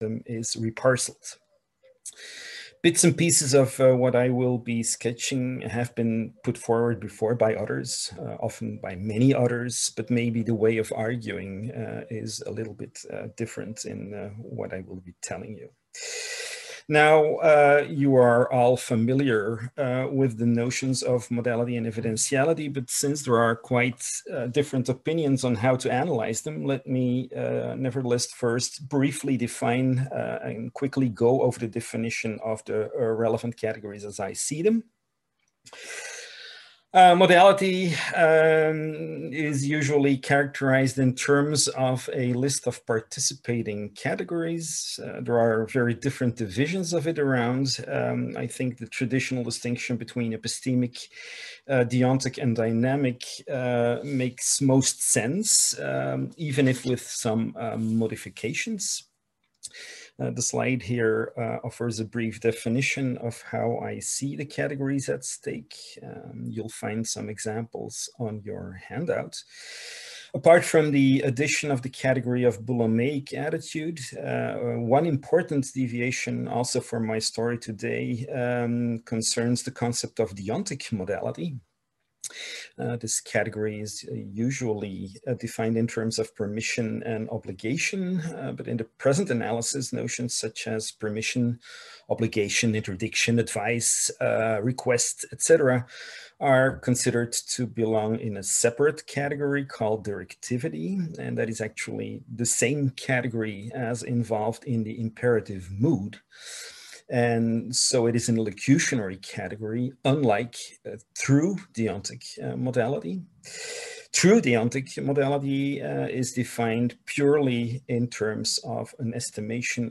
This talk focuses on whether or not the domains of evidentiality and modality relate, and if so, how they relate.